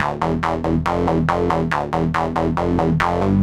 Index of /musicradar/future-rave-samples/140bpm